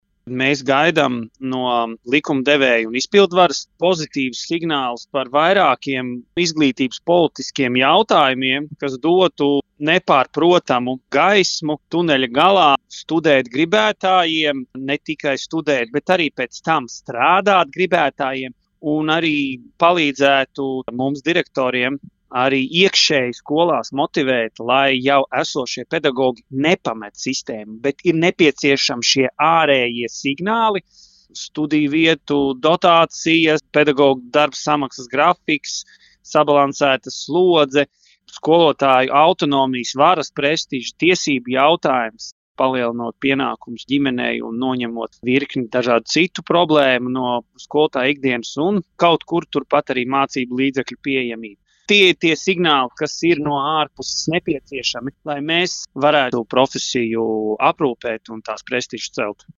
RADIO SKONTO Ziņās par to, kas nepieciešams, lai paaugstinātu pedagogu darba prestižu